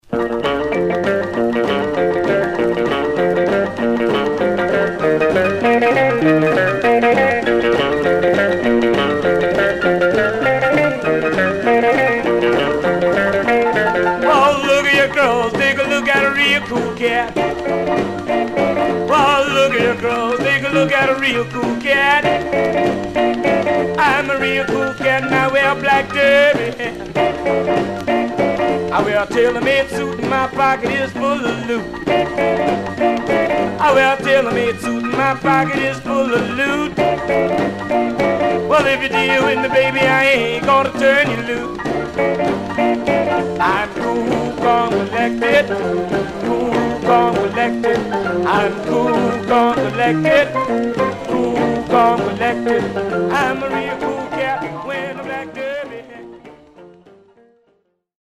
Some surface noise/wear
Mono
Rythm and Blues